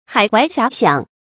海懷霞想 注音： ㄏㄞˇ ㄏㄨㄞˊ ㄒㄧㄚˊ ㄒㄧㄤˇ 讀音讀法： 意思解釋： 唐李白《秋夕書懷》詩「海懷結滄洲，霞想游赤城。」